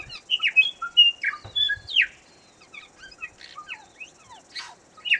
I washed some dishes, did a bit of laundry by hand and hung it up on the line outside to dry, recorded some bird sounds in case we wanted to use them as background in the video, and then began my big editing project.
A Bellbird was gracious enough to land right next to me and give me a beautful song.
bird-MVI_4658.AIF